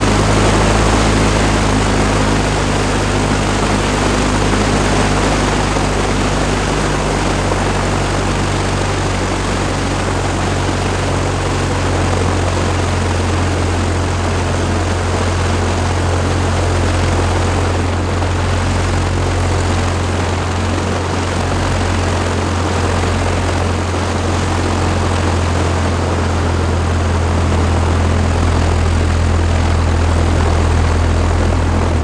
Propeller_Plane-44k_1.L.wav